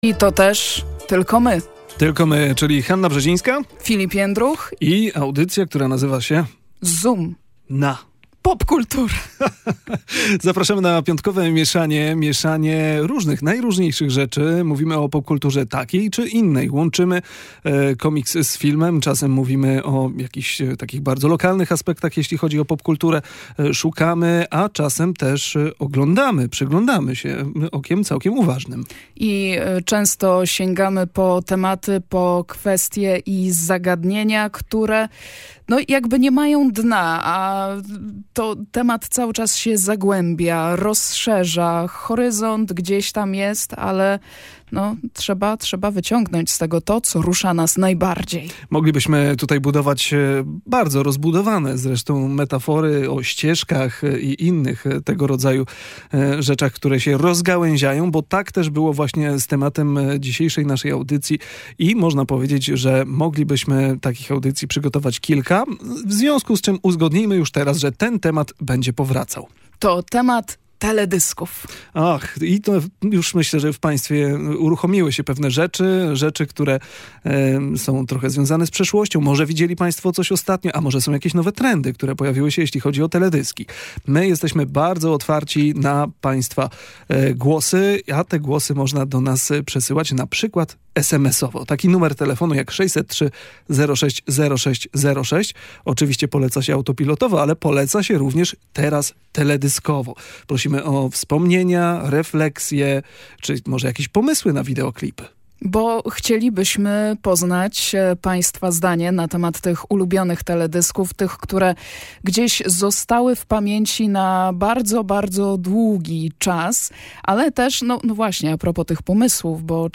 W audycji pojawił się także głos lokalnego twórcy!